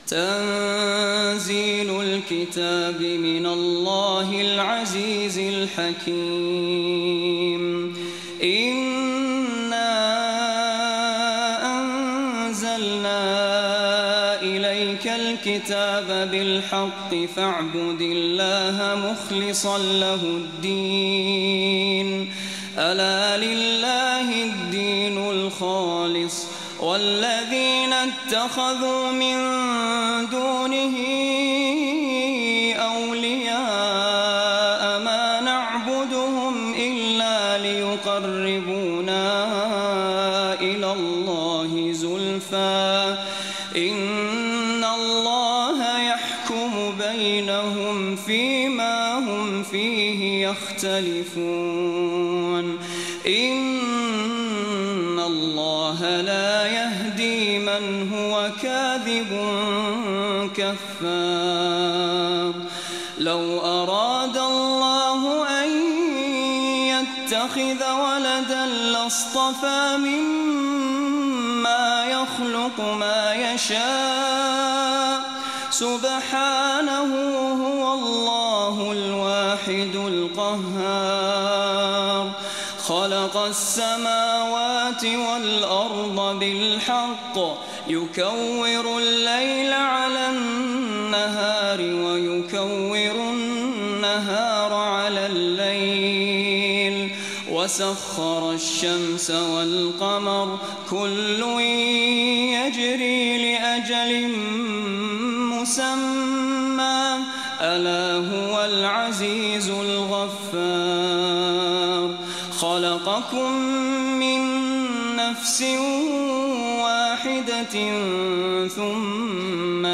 تلاوة هادئة